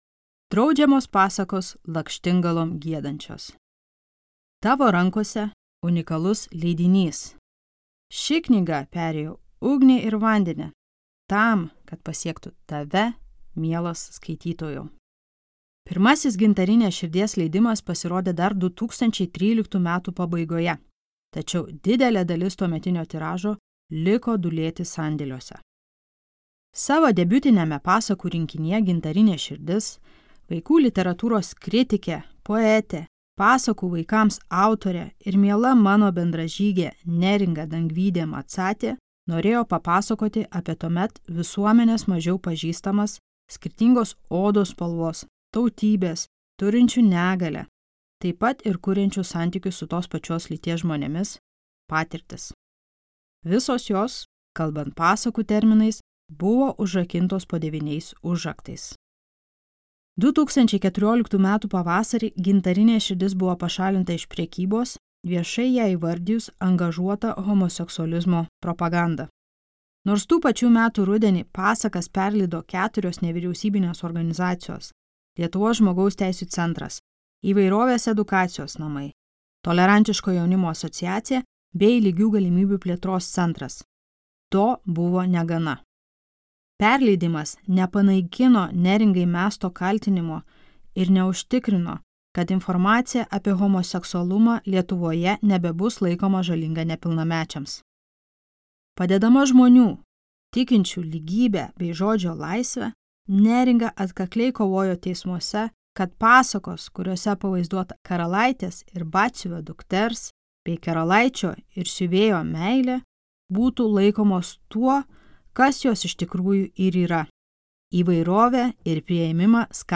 Neringos Dangvydės audioknyga vaikams „Gintarinė širdis“, į kurią sudėtos įvairios pasakos parengtos pagal liaudies, Brolių Grimų, Anderseno pasakų motyvus, ir kurias mažieji galės aptarti kartu su tėveliais pagal autorės kiekvienai pasakai paruoštas gaires.